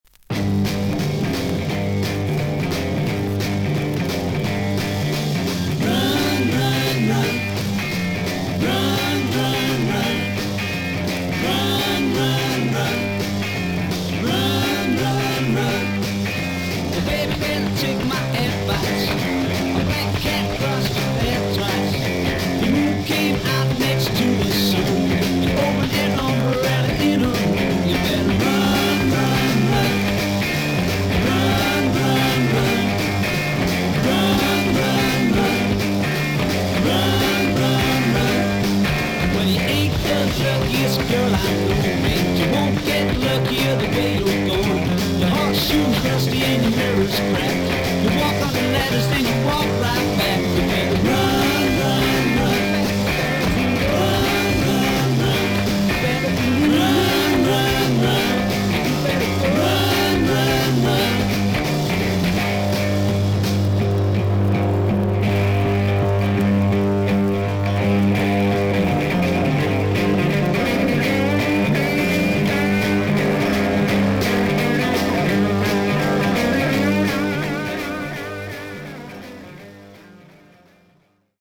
しかし音は見た目ほどは悪くなくVG+で所々に少々軽いパチノイズの箇所あり。少々サーフィス・ノイズあり。。